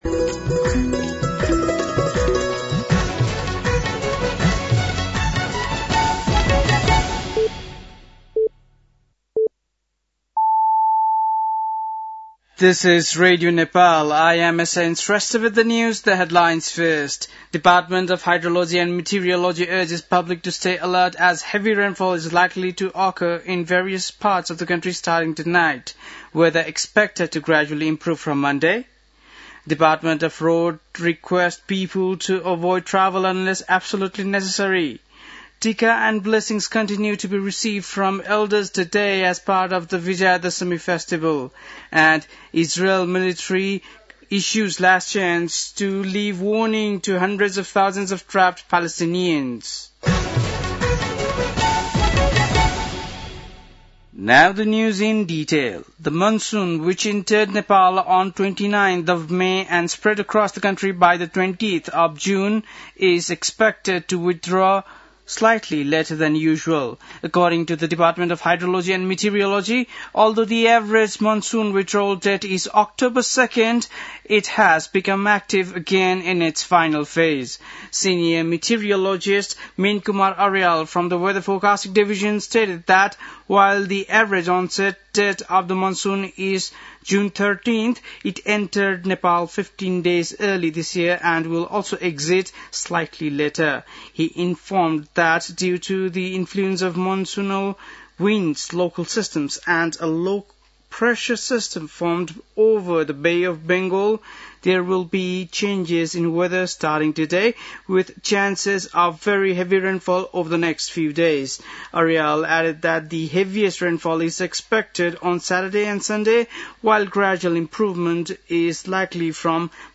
बेलुकी ८ बजेको अङ्ग्रेजी समाचार : १७ असोज , २०८२